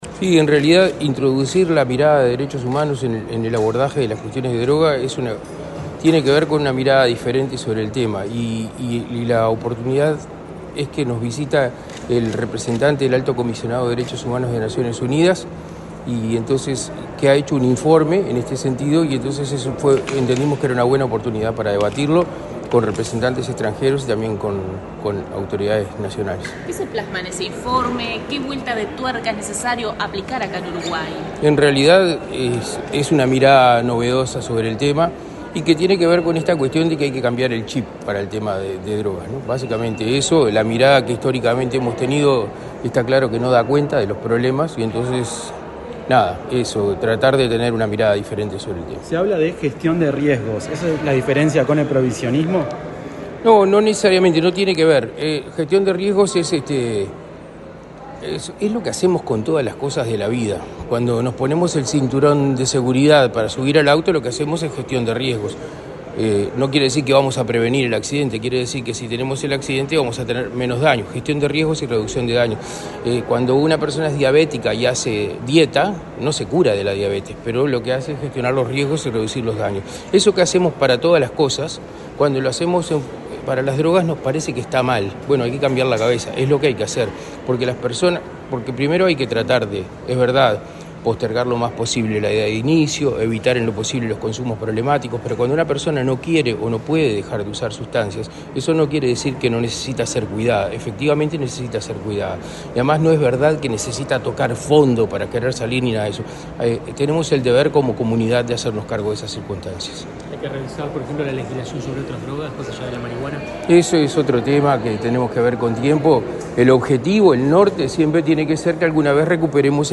Declaraciones del secretario general de Drogas, Daniel Radío
El secretario general de Drogas, Daniel Radío, dialogó con la prensa, antes de participar en el evento Diálogo Hemisférico sobre Derechos Humanos y